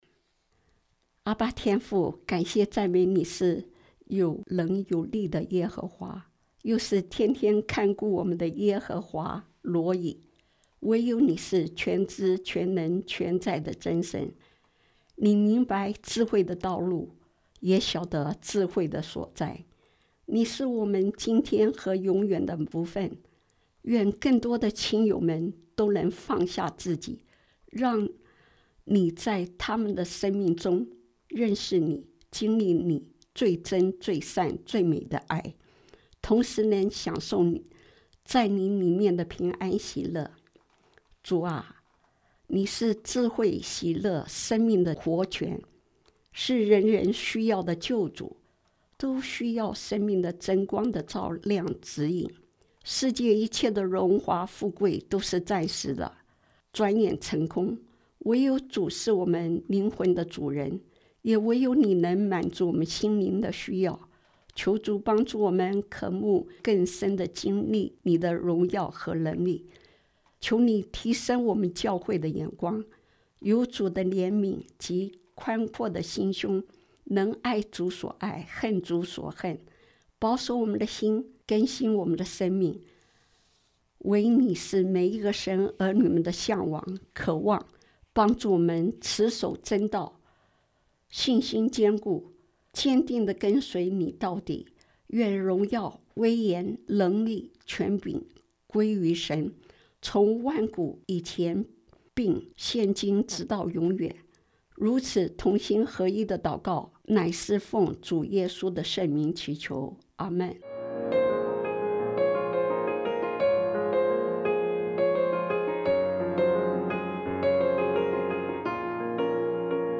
今日祈禱– 人人都需要的救主